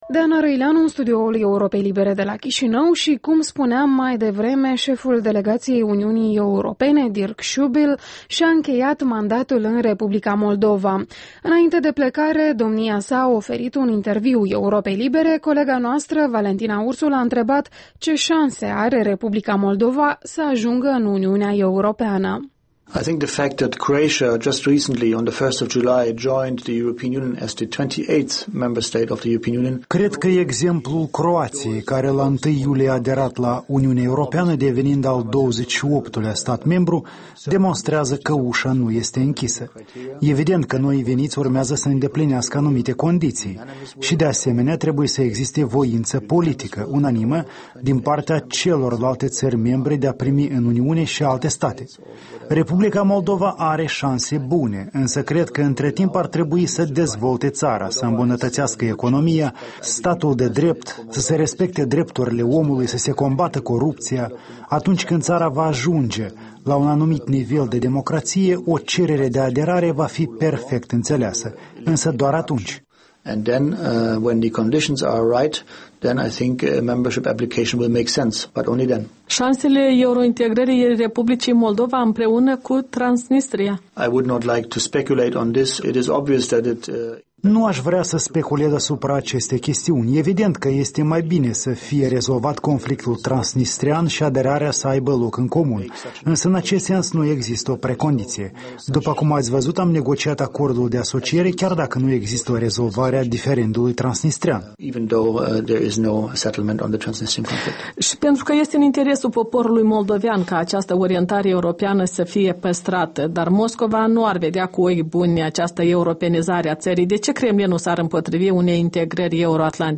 Interviul dimineții: cu diplomatul Dirk Schuebel la încheierea misiunii sale (II)